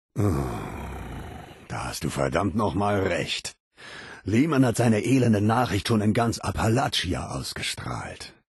Der innere Ghul: Audiodialoge